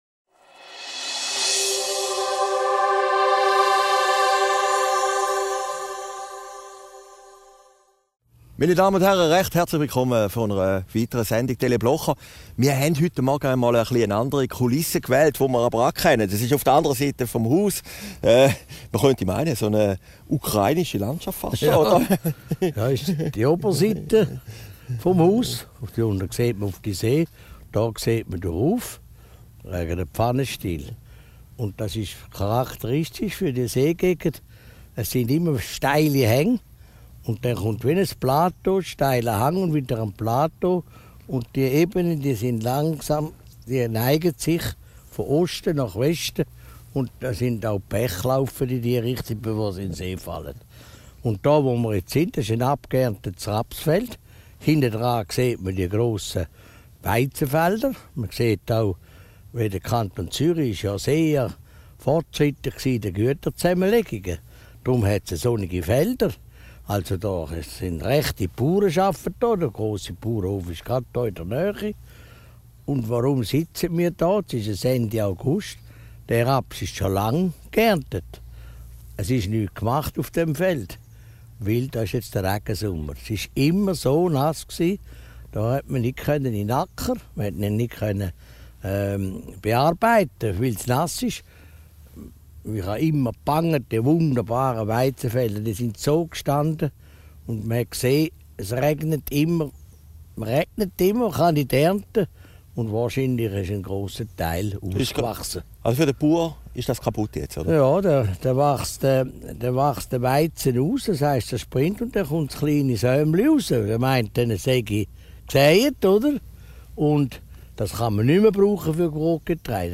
Video downloaden MP3 downloaden Christoph Blocher über Geri Müller, den Einmarsch in die Ukraine und den Russlandboykott Aufgezeichnet in Herrliberg, am 29.